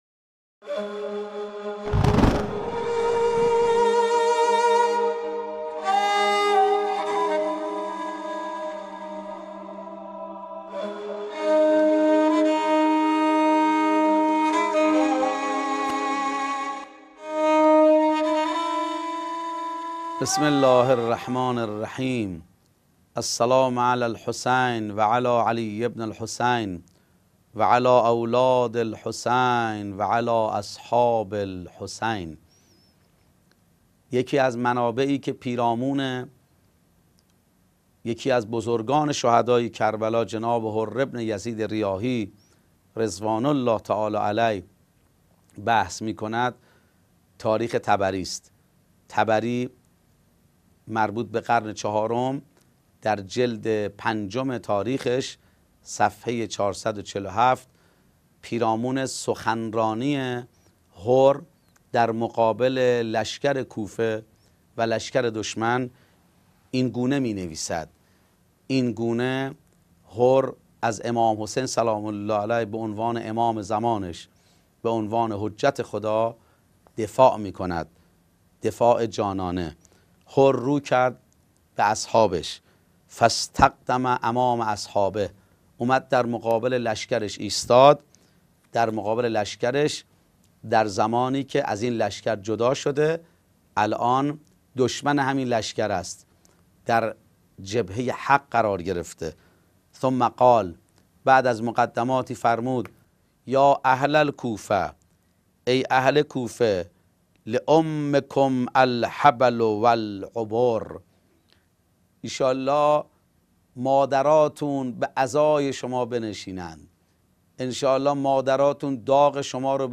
سلسله گفتارهایی پیرامون تبارشناسی عاشورا